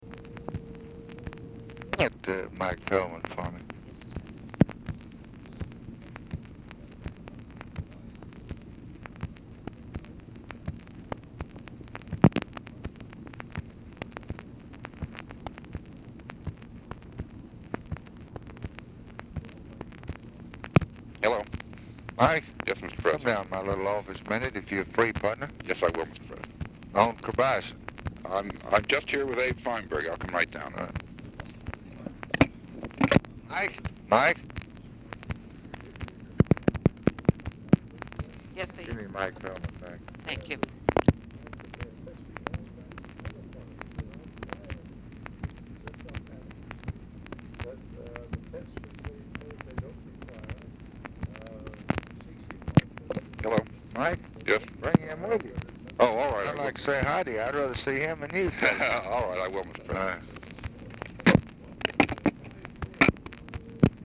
Telephone conversation # 2484, sound recording, LBJ and MYER FELDMAN, 3/12/1964, 12:35PM | Discover LBJ
2 BRIEF CALLS BACK-TO-BACK
Format Dictation belt
Oval Office or unknown location